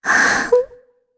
Shea-Vox-Deny_kr.wav